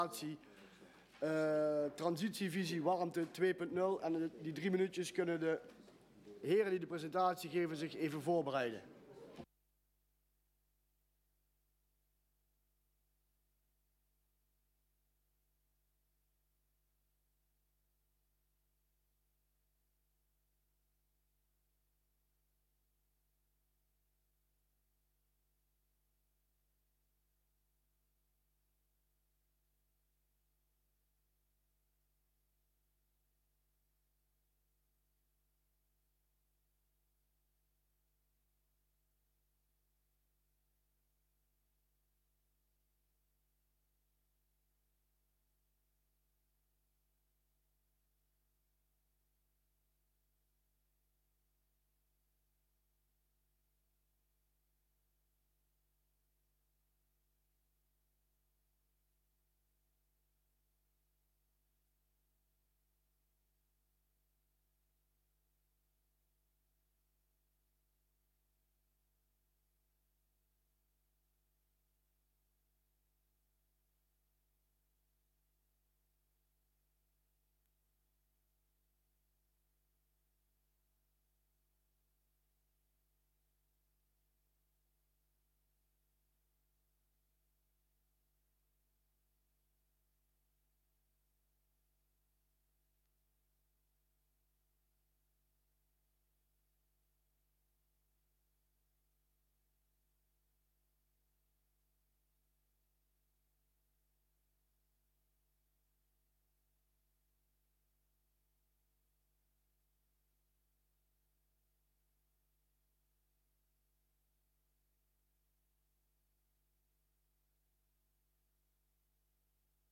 Agenda Beekdaelen - Raadscommissie Fysieke Leefomgeving donderdag 15 september 2022 19:00 - 23:00 - iBabs Publieksportaal